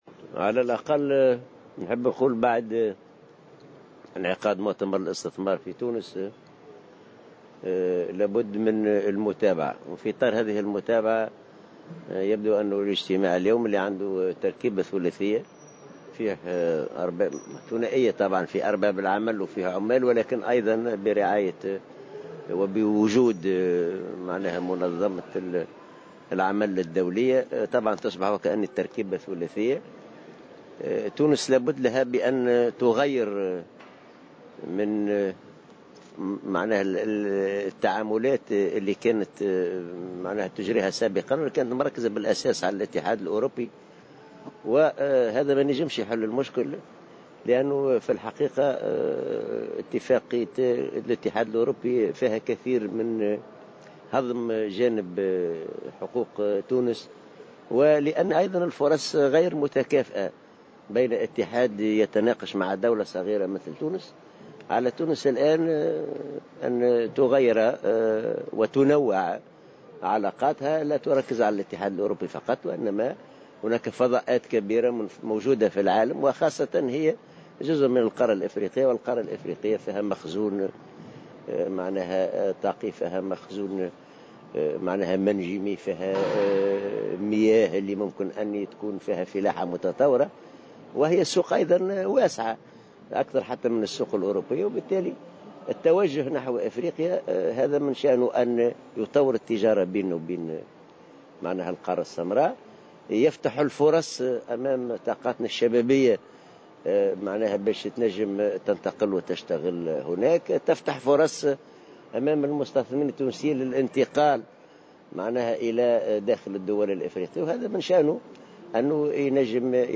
وأضاف العباسي في تصريح إعلامي على هامش انطلاق قمة الشركاء الاجتماعيين حول تنمية التشغيل بإفريقيا أنه على تونس أن تغيّر وتنوع علاقاتها و أن لا تجعلها مقتصرة فقط على الاتحاد الأوربي، مشيرا إلى أن هذه العلاقة غير متكافئة، وفق تعبيره.